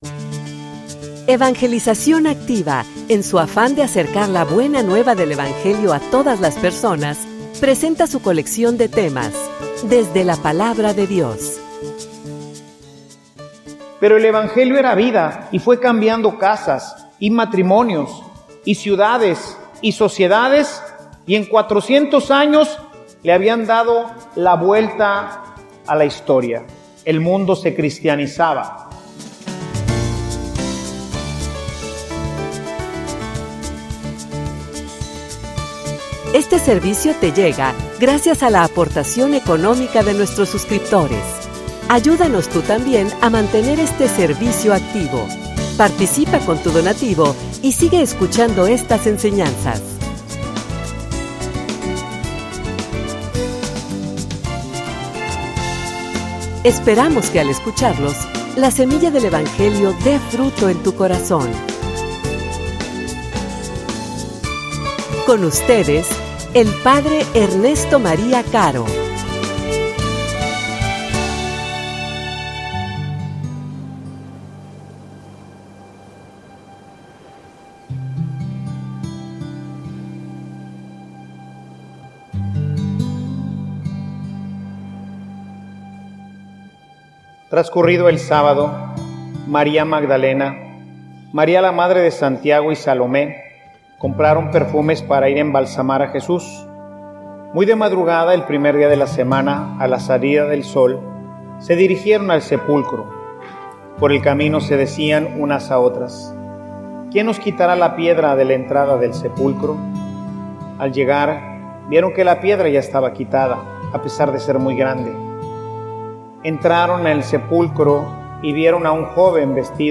homilia_La_esperanza_activa.mp3